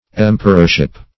Emperorship \Em"per*or*ship\, n. The rank or office of an emperor.